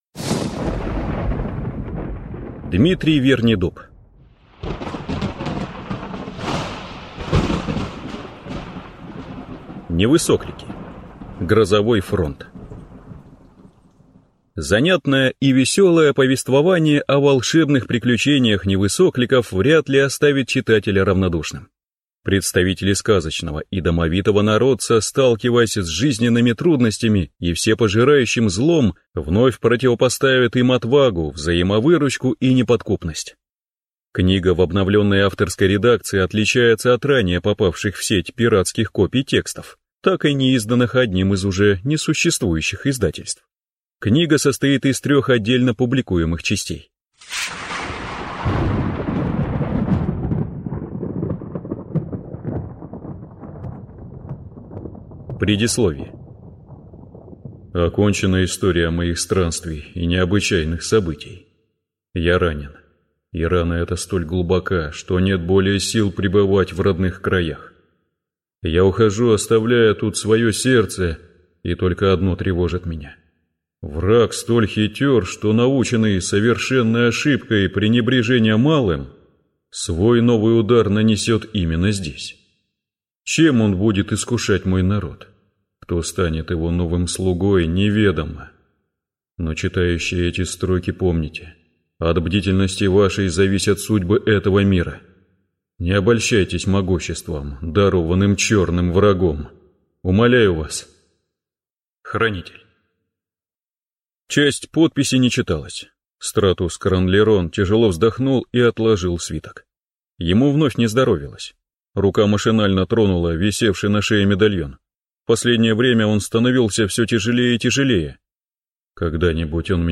Аудиокнига Невысоклики. Грозовой фронт | Библиотека аудиокниг